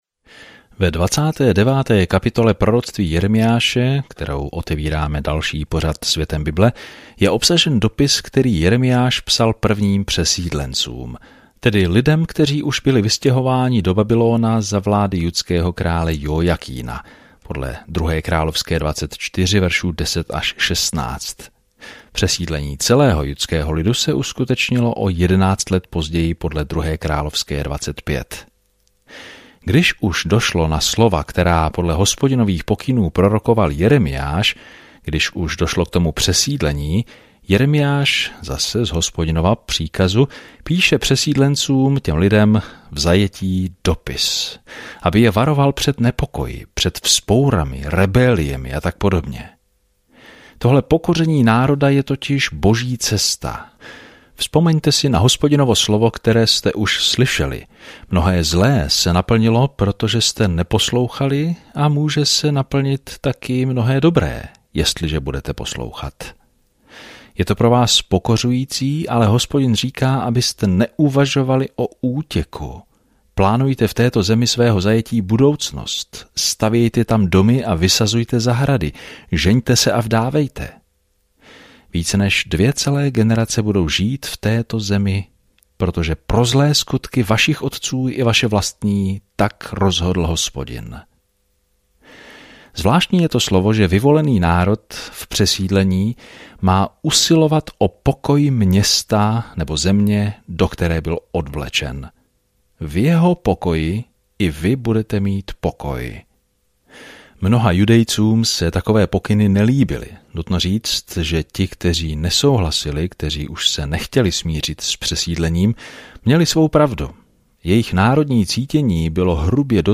Písmo Jeremiáš 29 Jeremiáš 30:1-3 Den 18 Začít tento plán Den 20 O tomto plánu Bůh si vybral Jeremiáše, muže něžného srdce, aby předal drsné poselství, ale lidé toto poselství nepřijímají dobře. Denně procházejte Jeremiášem, poslouchejte audiostudii a čtěte vybrané verše z Božího slova.